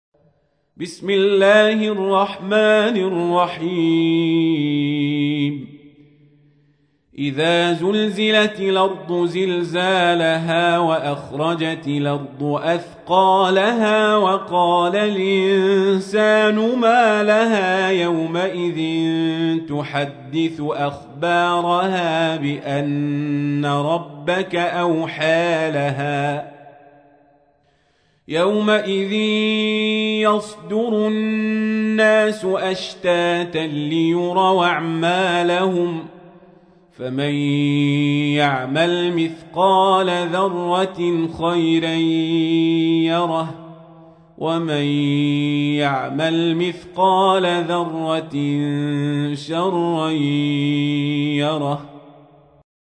تحميل : 99. سورة الزلزلة / القارئ القزابري / القرآن الكريم / موقع يا حسين